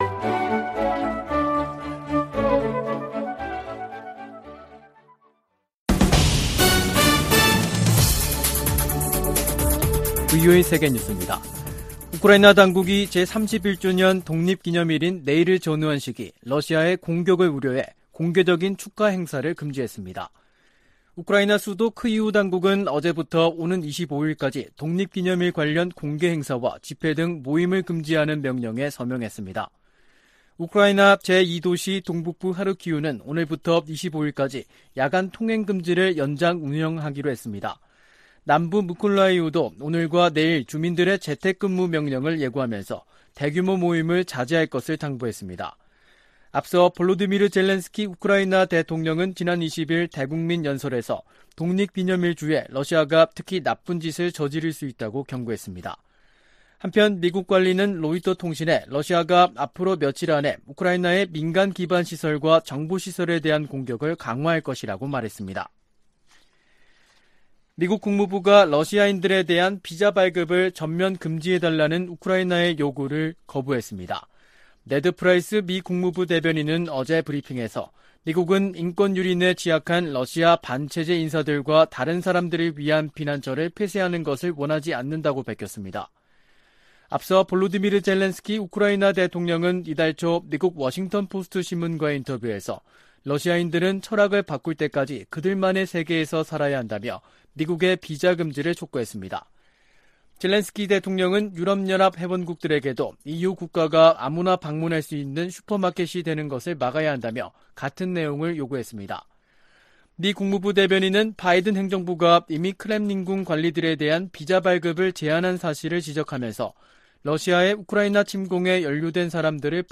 VOA 한국어 간판 뉴스 프로그램 '뉴스 투데이', 2022년 8월 23일 3부 방송입니다. 미 국무부는 한국 윤석열 정부의 ‘담대한 구상’이 미국 정부의 접근법과 일치한다며, 북한의 긍정적 반응을 촉구했습니다. 한국이 사상 처음으로 호주의 대규모 다국적 연합훈련인 피치블랙 훈련에 참가한다고 호주 국방부가 확인했습니다. 중국이 주한미군의 고고도 미사일 방어체계 즉 사드(THAAD)에 관해 한국에 압박을 이어가고 있습니다.